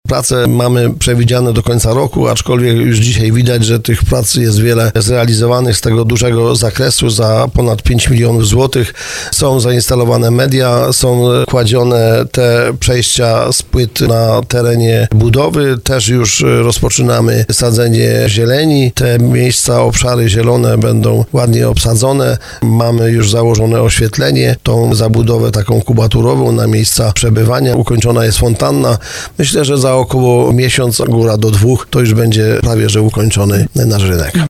Prace, które mają potrwać do końca roku, wprowadzają do centrum miasta więcej funkcjonalnych przestrzeni i przyjaznej infrastruktury dla mieszkańców i turystów. Jak mówi burmistrz Krzysztof Kaczmarski, po modernizacji rynek ma być miejscem zielonym, funkcjonalnym i sprzyjającym wypoczynkowi.